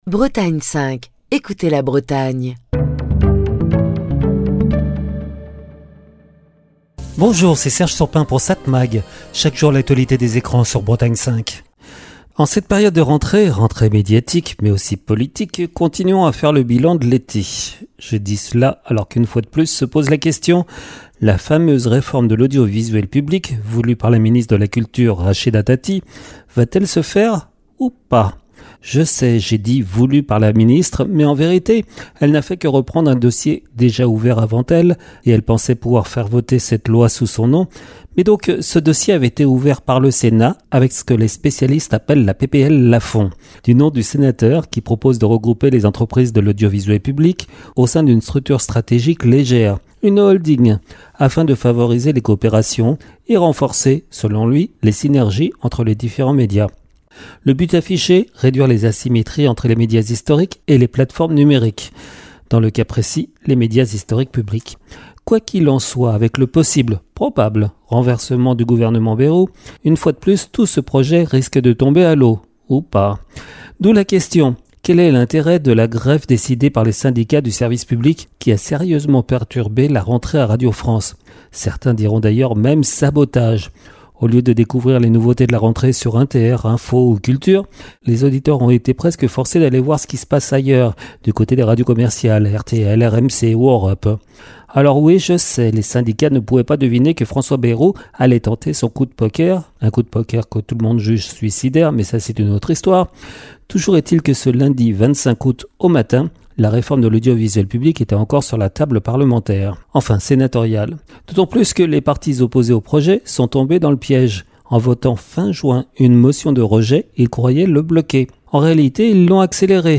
Chronique du 28 août 2025.